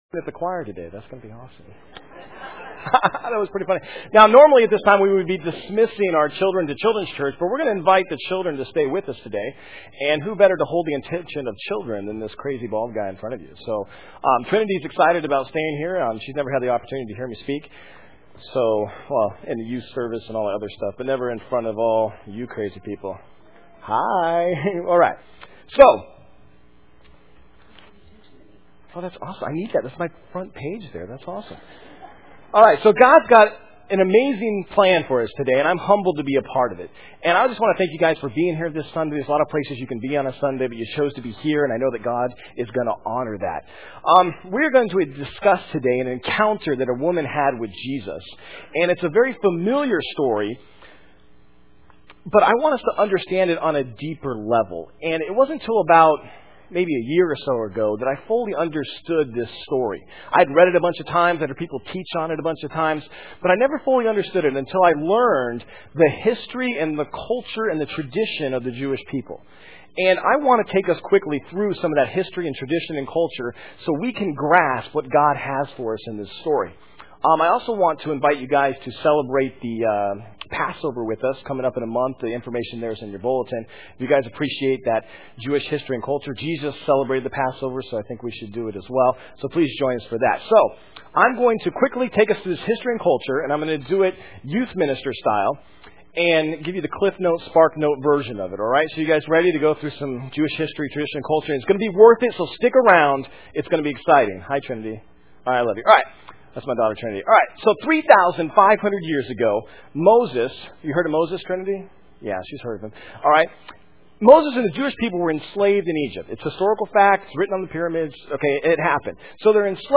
Main Service am